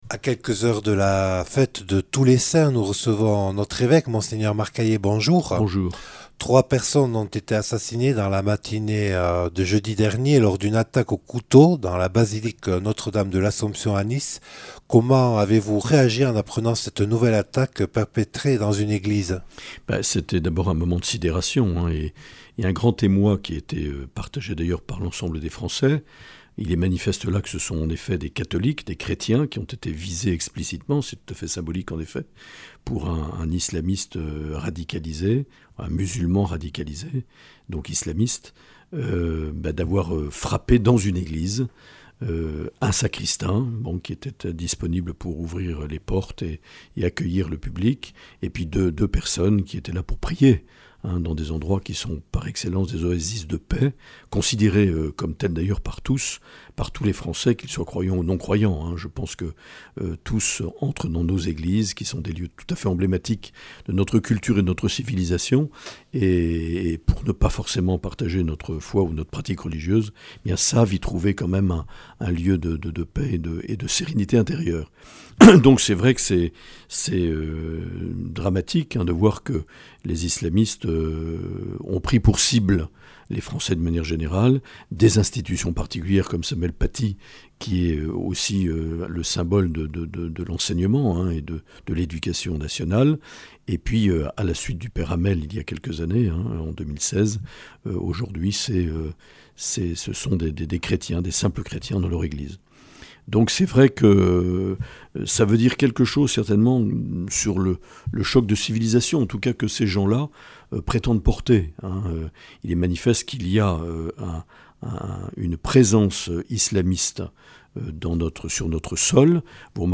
Accueil \ Emissions \ Infos \ Interviews et reportages \ Mgr Aillet : « Je déplore l’interdiction des cultes publics à partir du mardi (...)